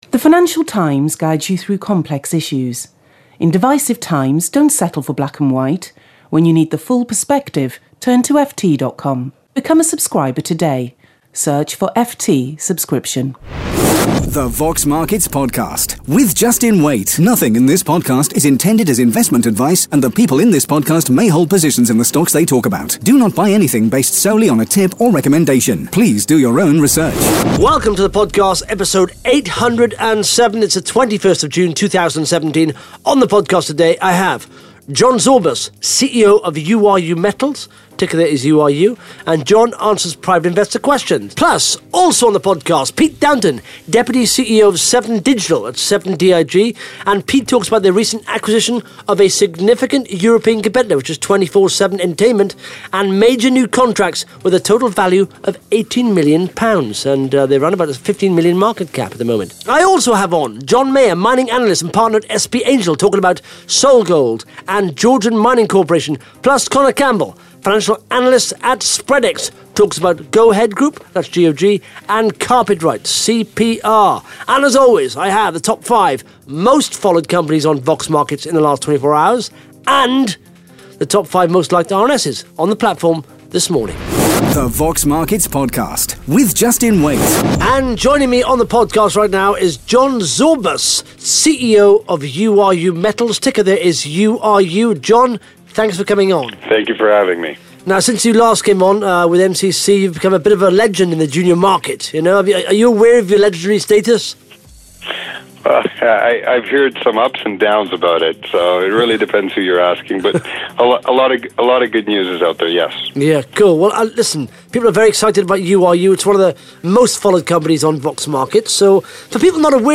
(Interview starts at 1 minute 24 seconds)